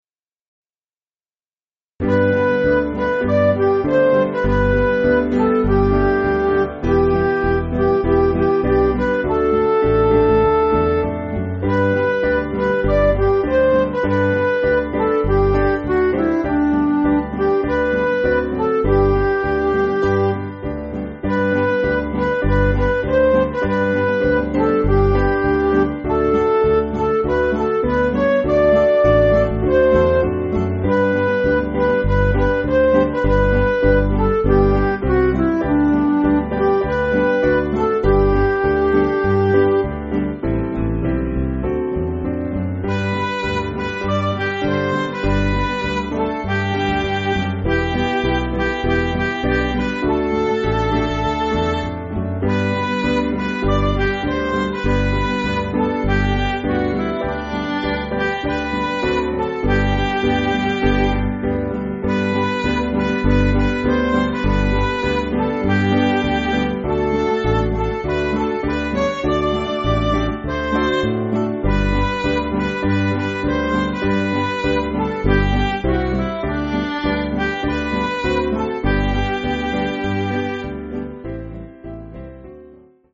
Piano & Instrumental
(CM)   4/G